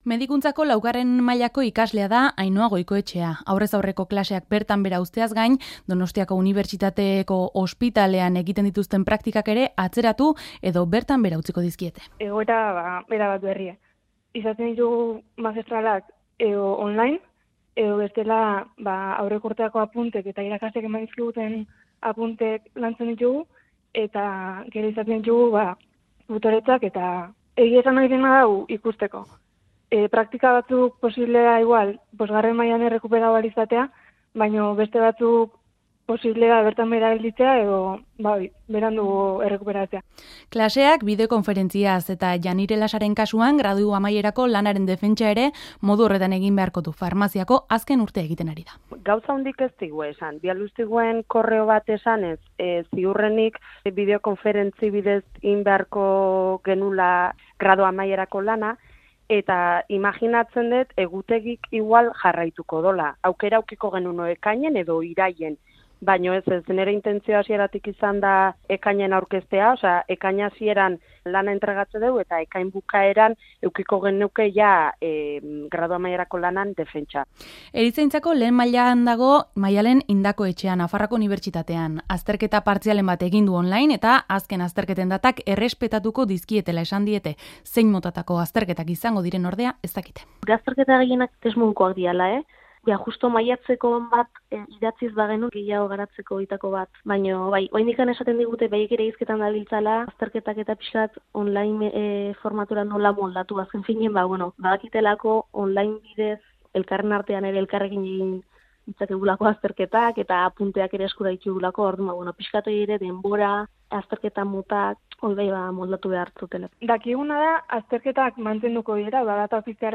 Audioa: Pandemiaren eraginez, on-line ikasten ari diren unibertsitate mailako ikasleen testigantzak.